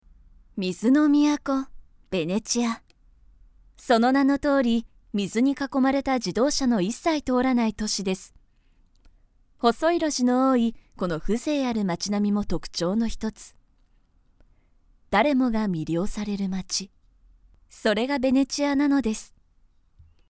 I am capable of bright and energetic narration, but I am also good at expressing things in depth.
– Narration –
Spacious